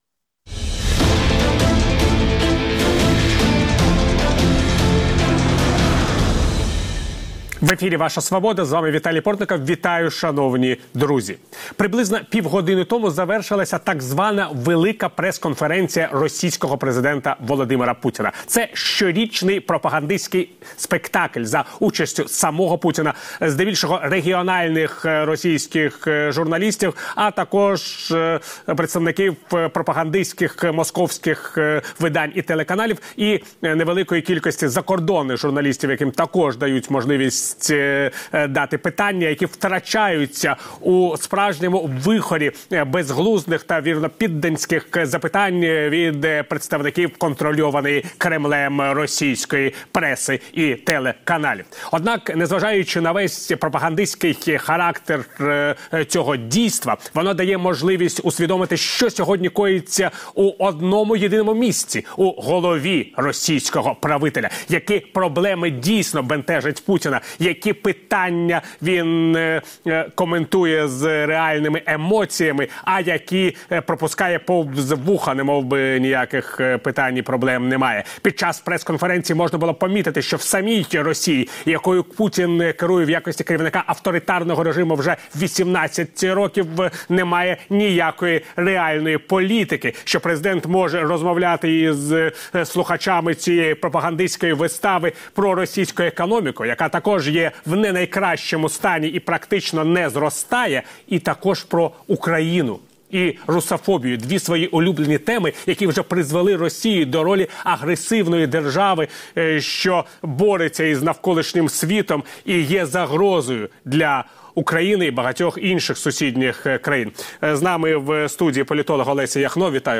політолог; Ілля Пономарьов, екс-депутат Держдуми Росії